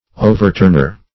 Overturner \O`ver*turn"er\, n. One who overturns.